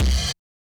kick04.wav